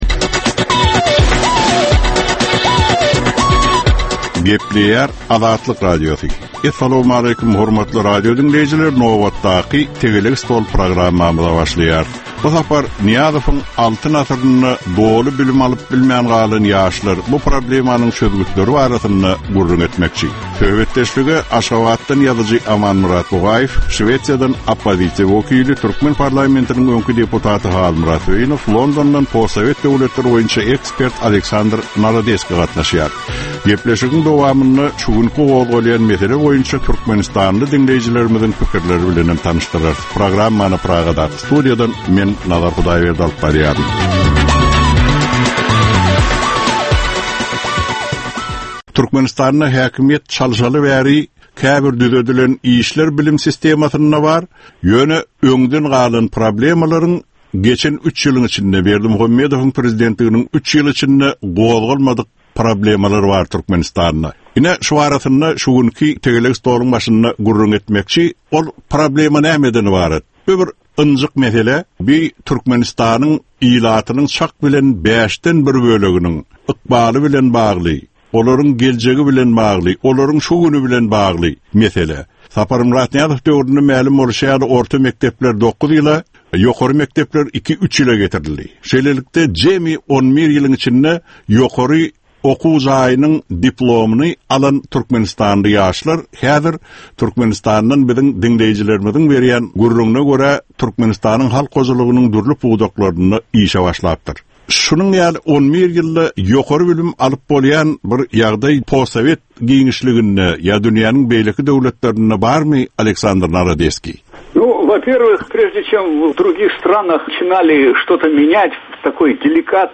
Jemgyýetçilik durmuşynda bolan ýa-da bolup duran soňky möhum wakalara ýa-da problemalara bagyşlanylyp taýýarlanylýan ýörite Tegelek stol diskussiýasy. 30 minutlyk bu gepleşikde syýasatçylar, analitikler we synçylar anyk meseleler boýunça öz garaýyşlaryny we tekliplerini orta atýarlar.